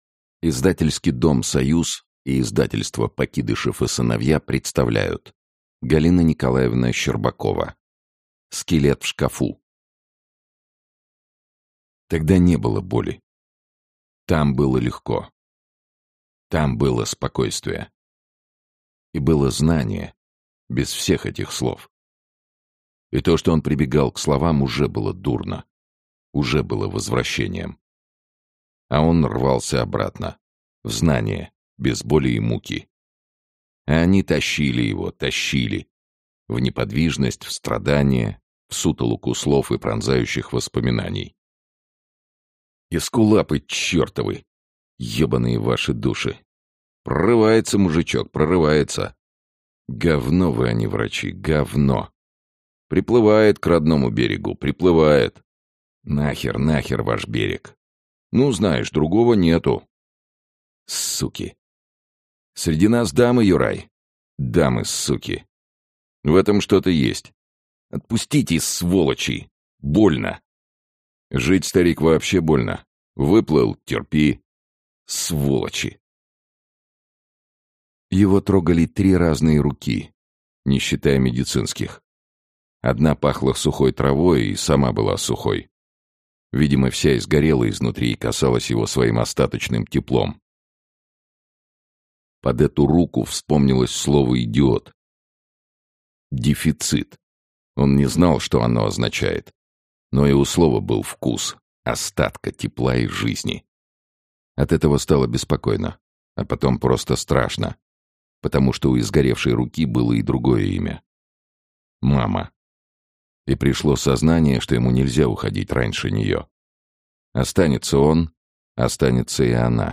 Аудиокнига Скелет в шкафу | Библиотека аудиокниг
Aудиокнига Скелет в шкафу Автор Галина Щербакова Читает аудиокнигу Сергей Чонишвили.